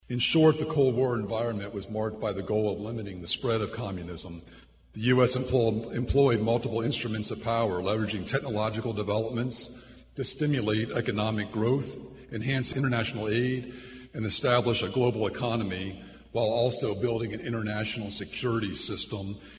The annual parade and Ceremony of Honor recognized “Veterans of the Cold War.”